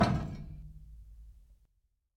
Index of /nodejsapp/foundryvtt/public/sounds/doors/metal/
heavy-sliding-test.ogg